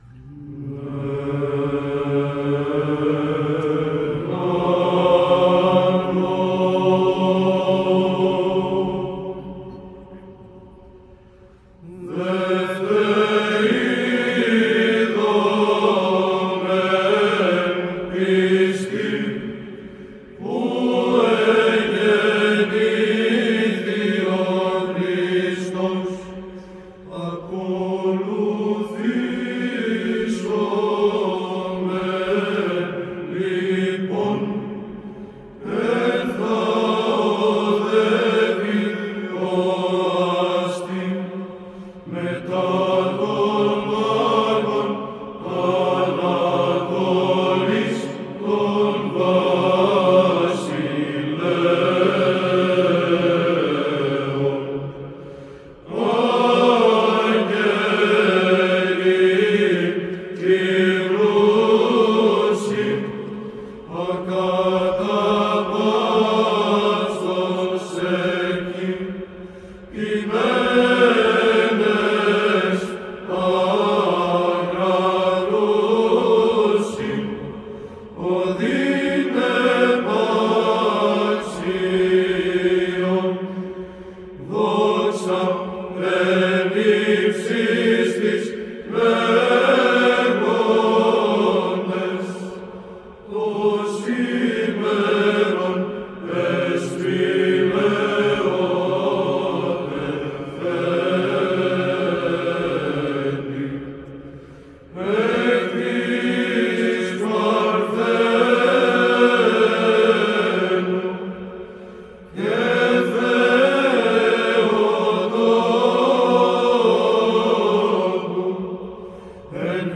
ΒΥΖΑΝΤΙΝΗ ΧΟΡΩΔΙΑ ΕΡΕΥΝΗΤΙΚΟΥ ΩΔΕΙΟΥ ΧΑΛΚΙΔΟΣ
Ψάλλει η Β.Χ.Ε.Ω. Χαλκίδος
ΤΙΤΛΟΣ:  Δεύτε ίδωμεν πιστοί... Κάθισμα της Εορτής.
ΗΧΟΣ:   Τέταρτος.